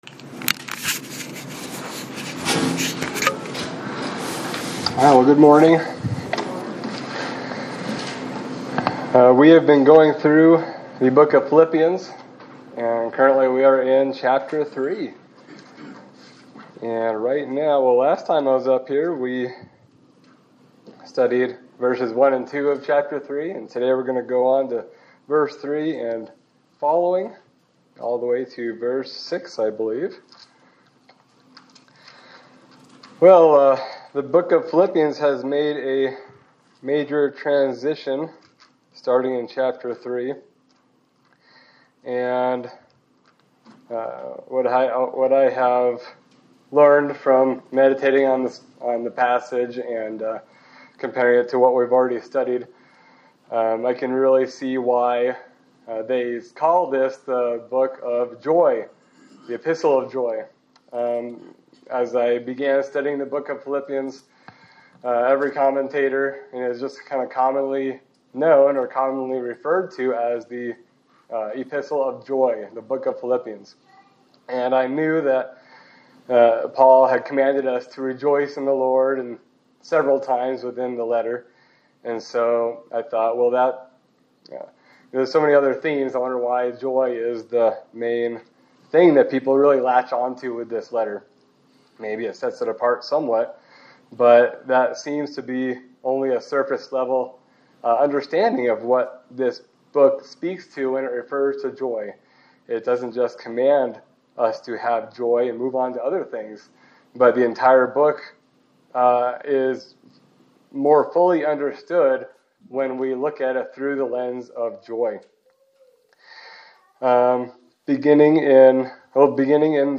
Sermon for August 3, 2025
Service Type: Sunday Service